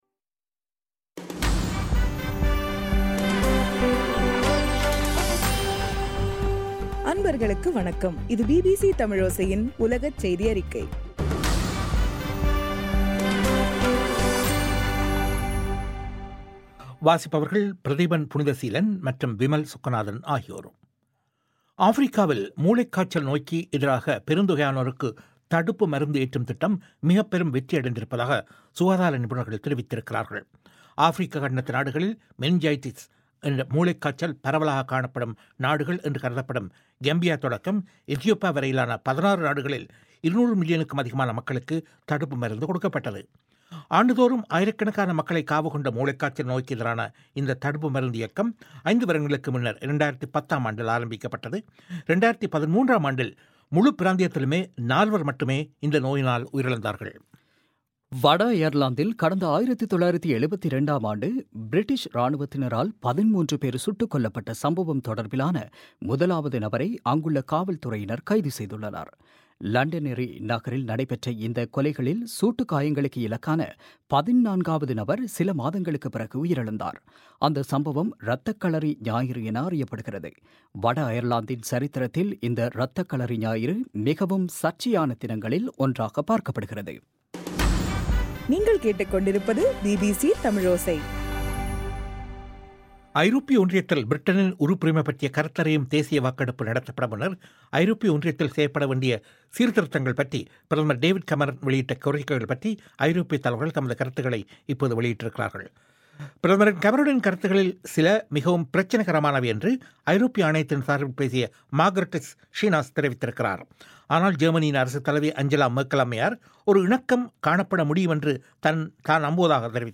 இன்றைய (நவம்பர் 10) பிபிசி தமிழோசை செய்தியறிக்கை